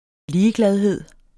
Udtale [ ˈliːəˌglaðˌheðˀ ]